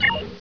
hail_outgoing.wav